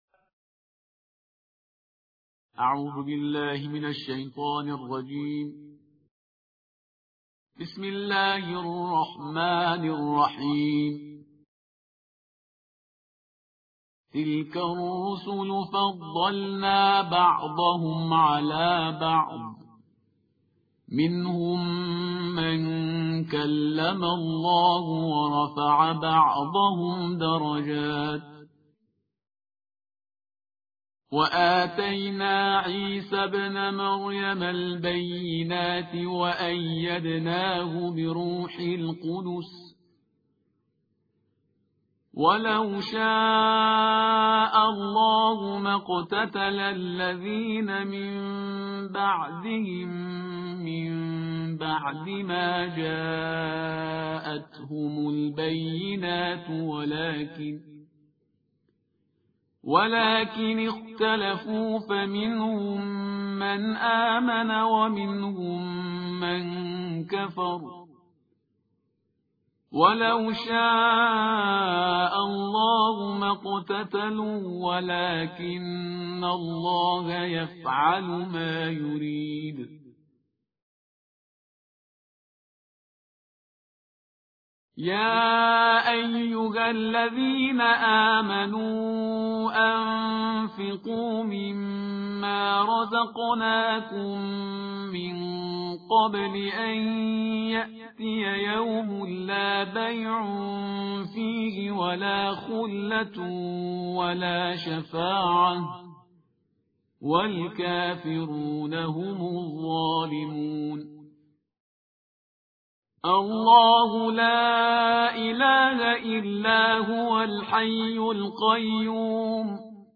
ترتیل جزء سوم قرآن کریم/استاد پرهیزگار